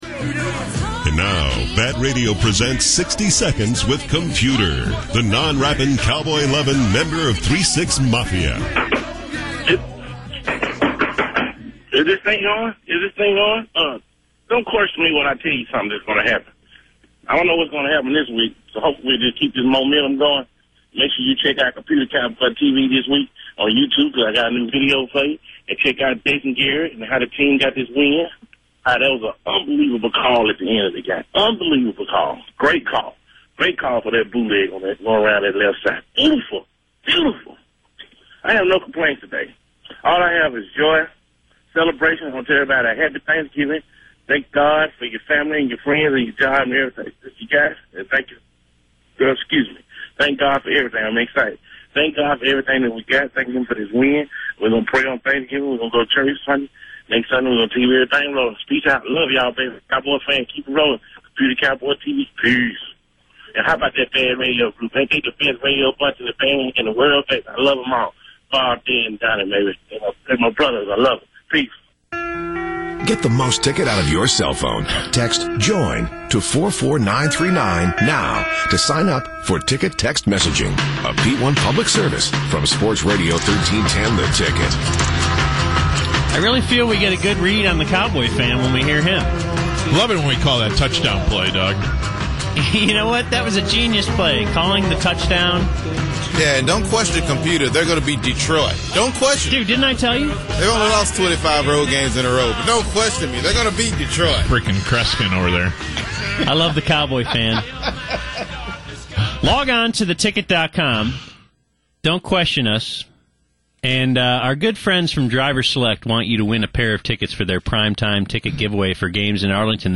It’s pretty tough to describe what happened in this segment, but it involves some of my favorite radio stop-downs: